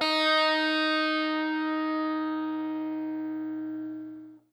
SPOOKY    AN.wav